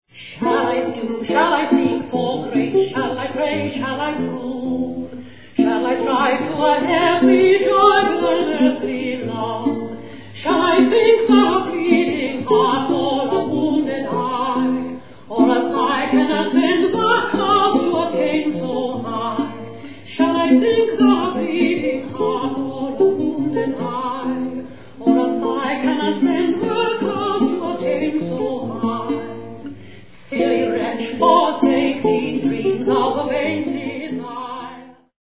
accompanied in the true Elizabethan performance style